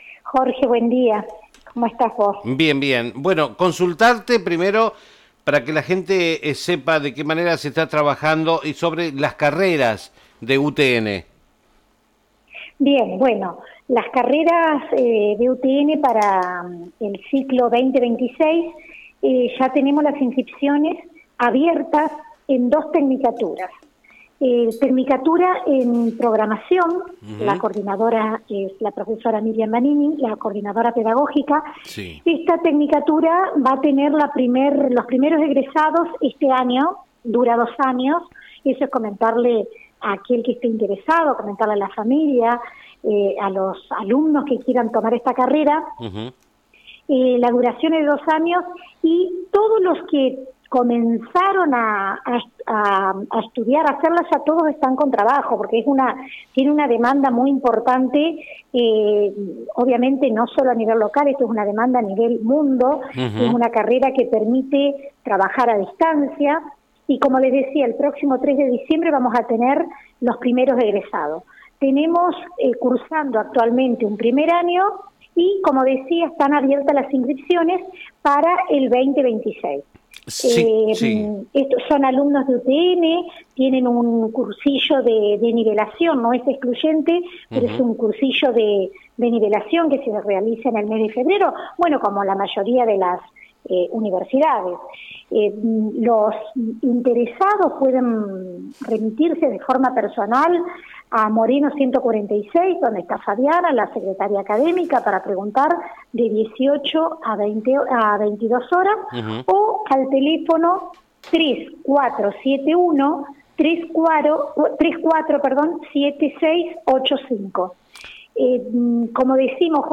Diálogo con Rosana Siri (Secretaria de Educación y Desarrollo Integral)